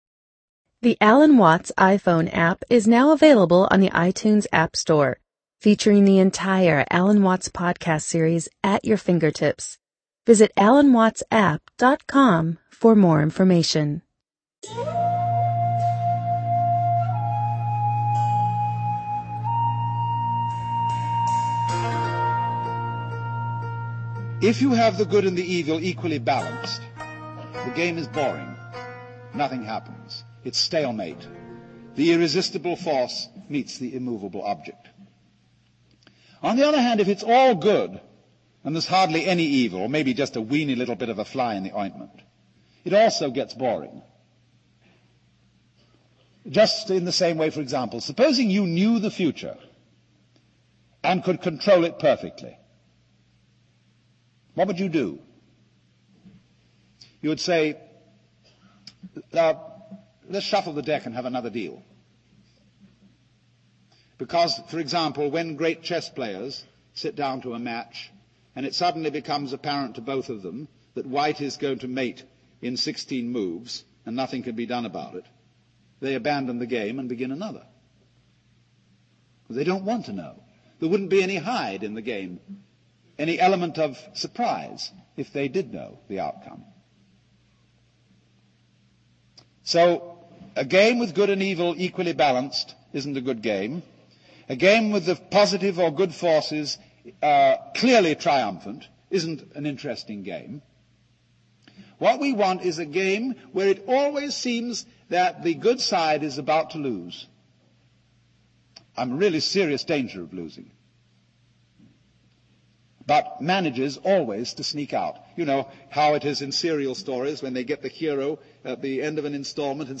You are listening to a sample of The Essential Lectures of Alan Watts.